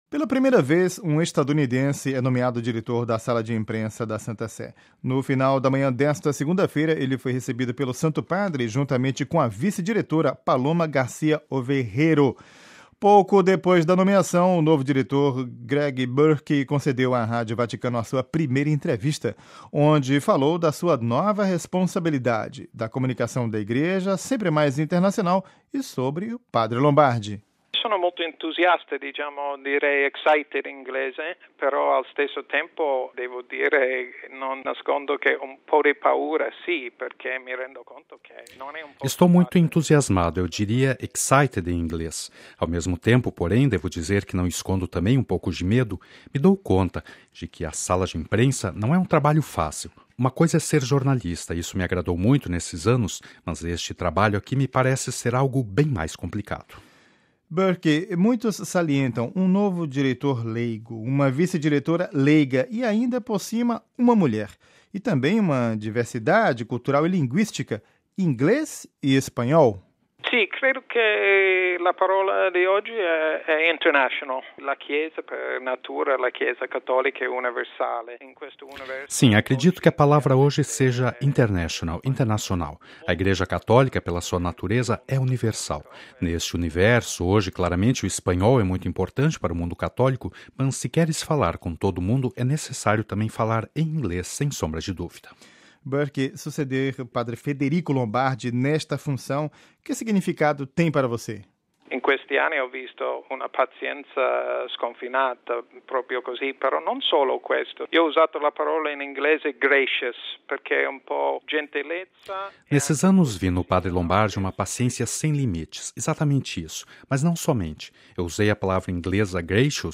Pouco depois da nomeação, o novo Diretor, Greg Burke, concedeu à Rádio Vaticano a sua primeira entrevista, onde falou da sua nova responsabilidade, da comunicação da Igreja sempre mais internacional e sobre o Padre Lombardi.